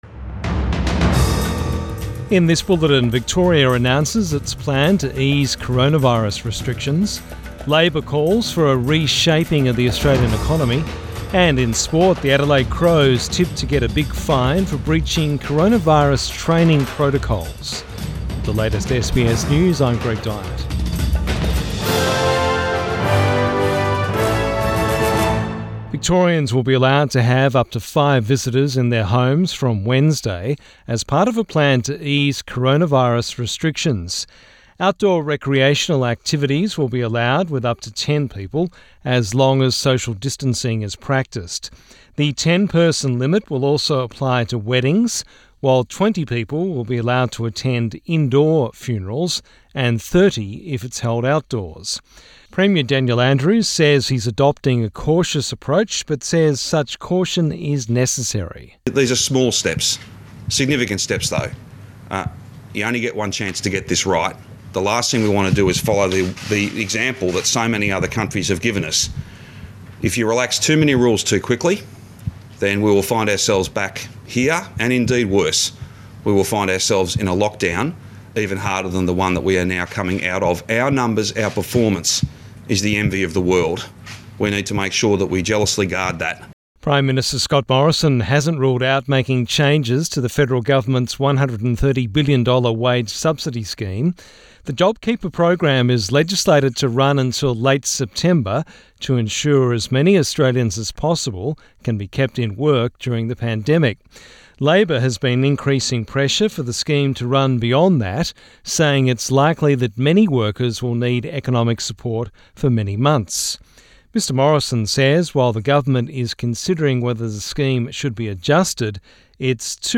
PM bulletin 11 May 2020